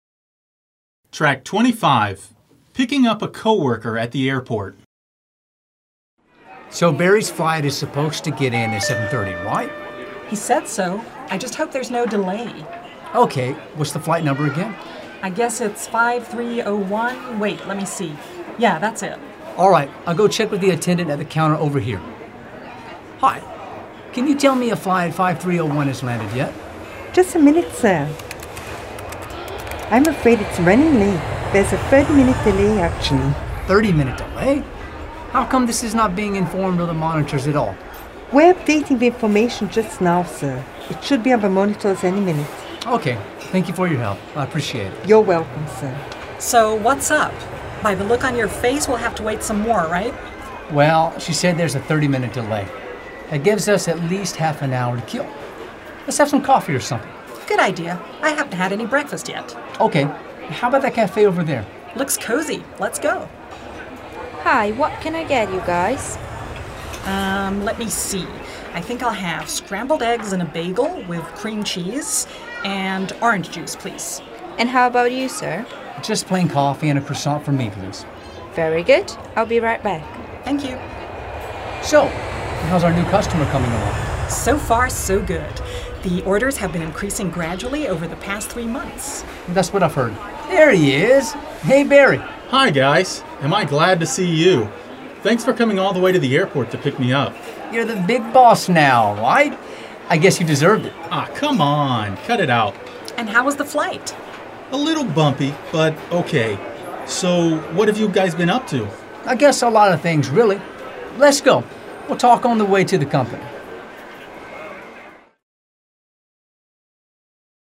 Click the link below to listen to the dialogue “Picking up a coworker at the airport” and then do the activities that follow.